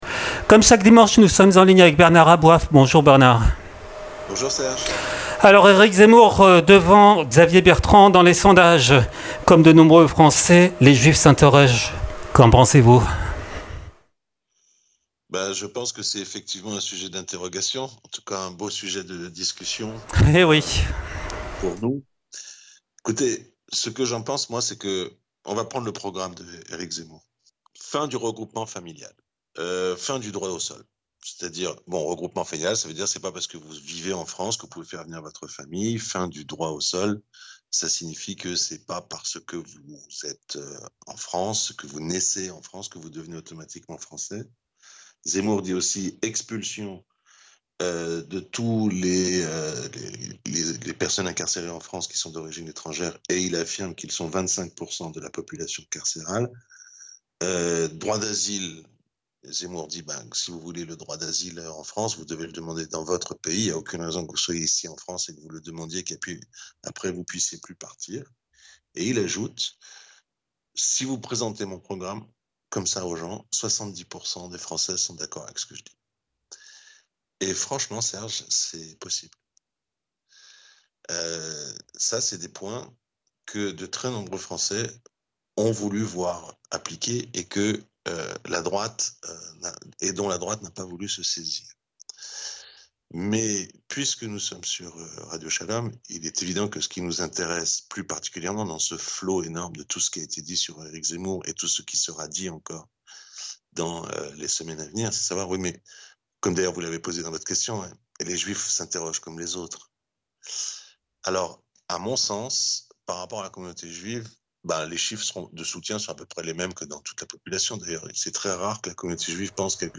RADIO SHALOM EN DIRECT